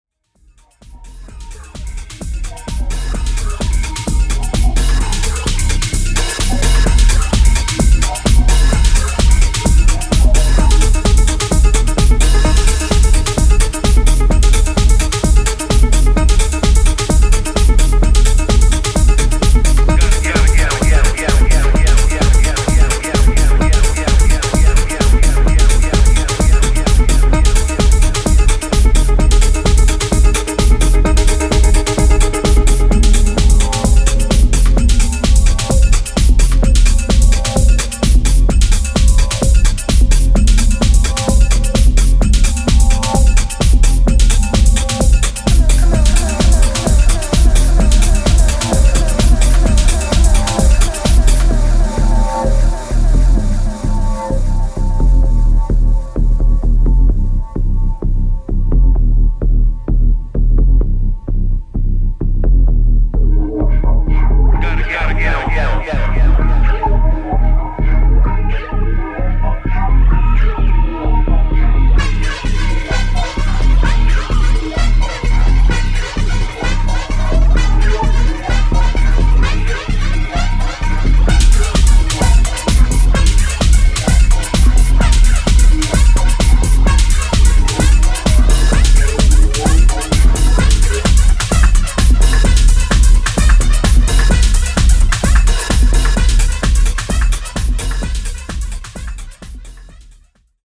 UKテック・ハウスの真髄ここにあり。